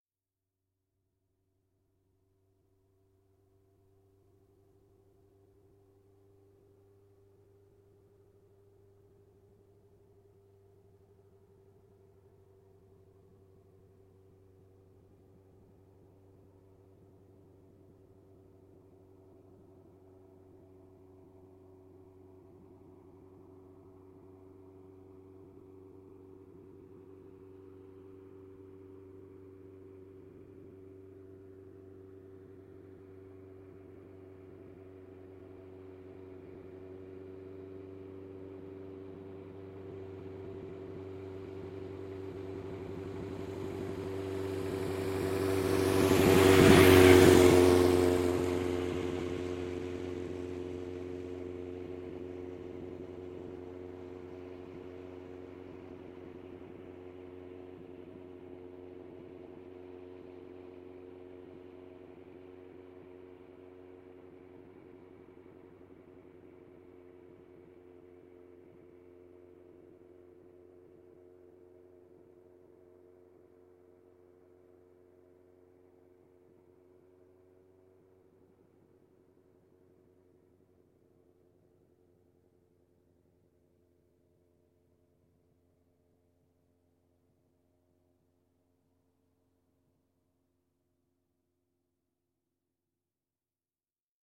Звук мотоциклиста, движущегося вдали и проезжающего мимо
• Категория: Мотоциклы и мопеды
• Качество: Высокое